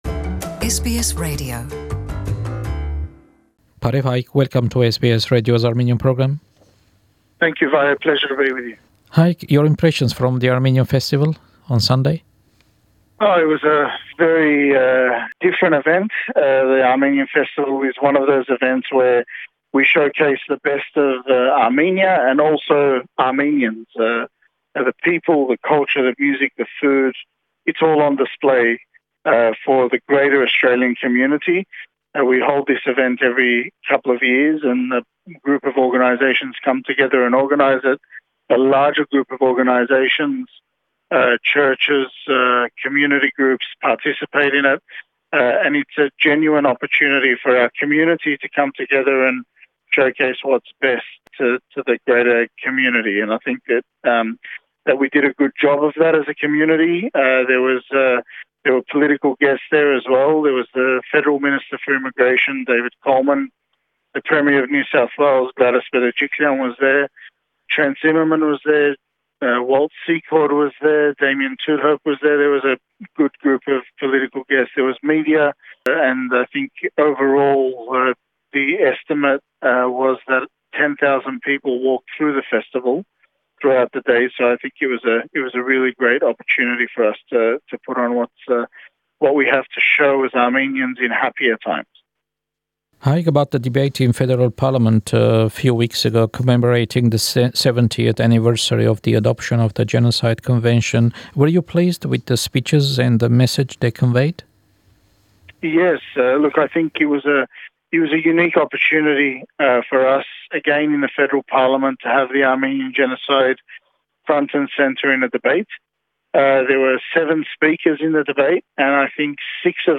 Հարցազրոյց